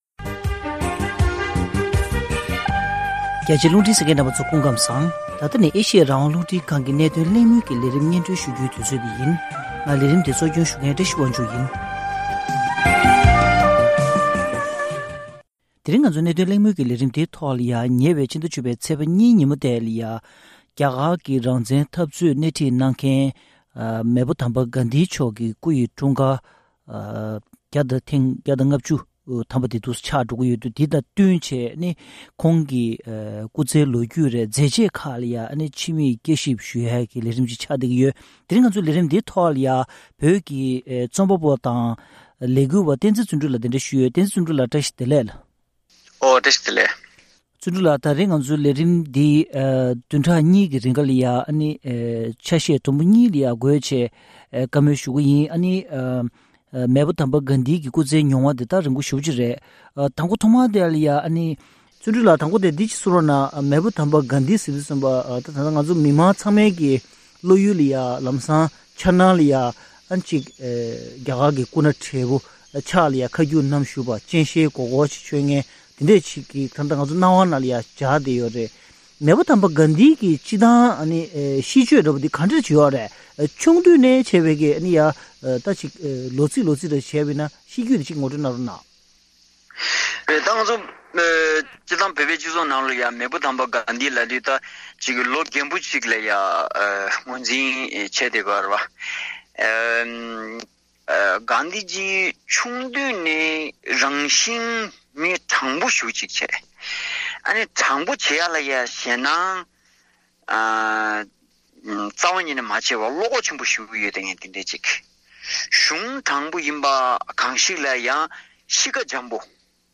༄༅༎གནད་དོན་གླེང་མོལ་གྱི་ལས་རིམ་ནང་།